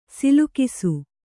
♪ silukisu